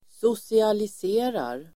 Ladda ner uttalet
Uttal: [sosialis'e:rar]